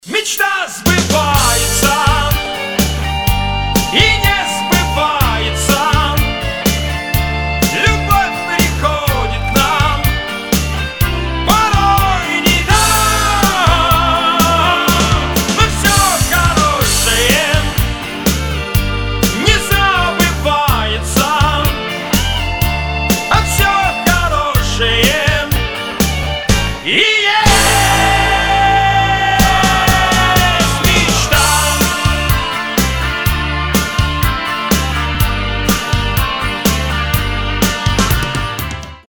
Танцевальные рингтоны